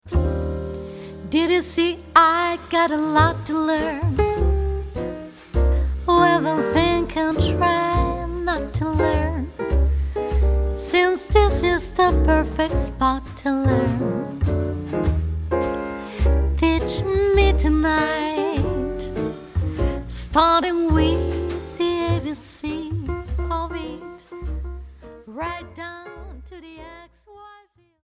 voice
piano
bass
drums